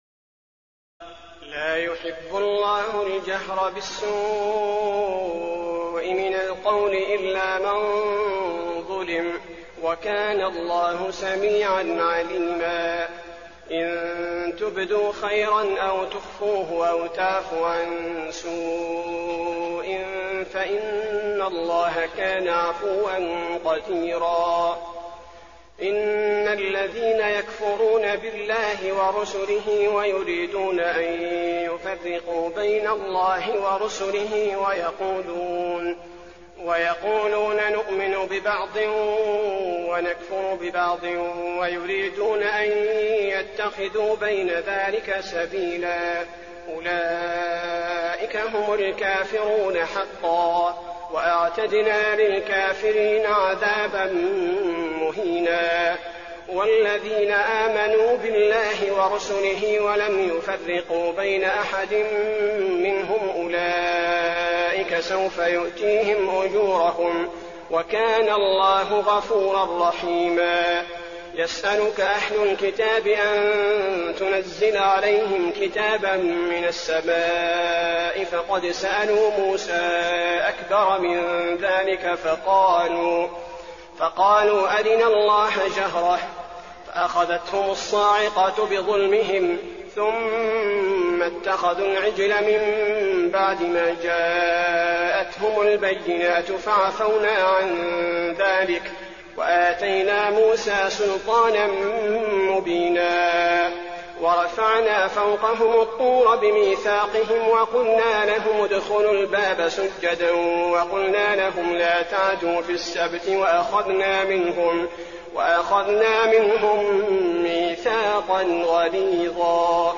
تراويح الليلة السادسة رمضان 1422هـ من سورتي النساء (148-176) و المائدة (1-26) Taraweeh 6 st night Ramadan 1422H from Surah An-Nisaa and AlMa'idah > تراويح الحرم النبوي عام 1422 🕌 > التراويح - تلاوات الحرمين